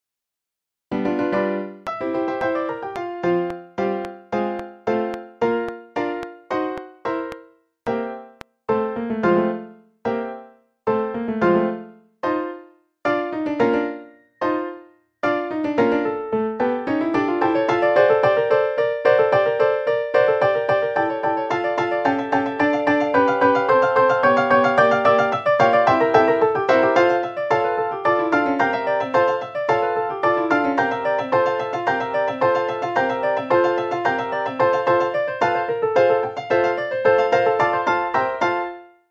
Midi Practice Tracks:
*These were created using MIDI with the first violin part removed. I intensely dislike the midi orchestral instrument sounds, so I changed all the instruments to the piano patch.
Quarter Note = 110